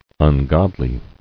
[un·god·ly]